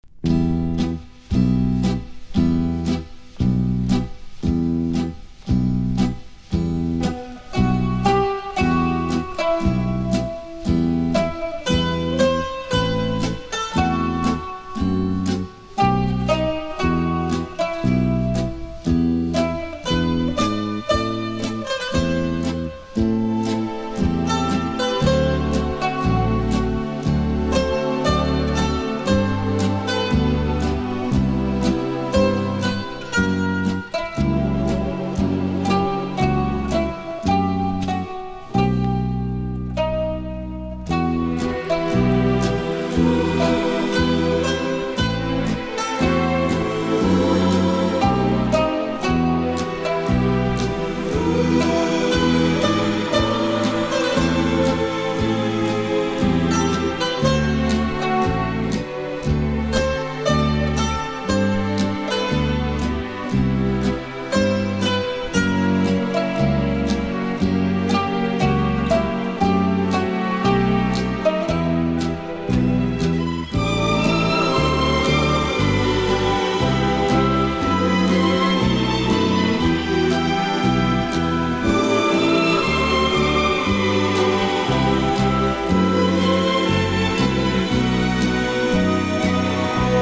# FUSION / JAZZ ROCK# サンプリング / ブレイク# 和ジャズ# 和モノ